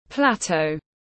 Cao nguyên tiếng anh gọi là plateau, phiên âm tiếng anh đọc là /ˈplæt.əʊ/.
Plateau /ˈplæt.əʊ/